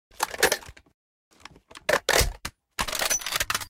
kraber_reload_empty.ogg